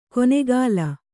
♪ konegāla